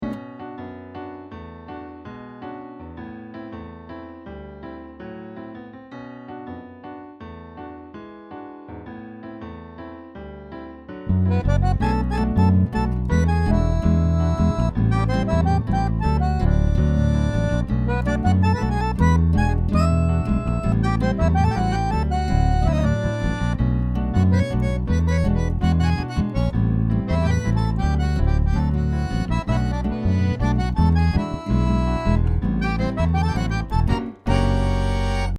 Миди луп бы к такому стилю( латиносовское)